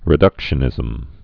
(rĭ-dŭkshə-nĭzəm)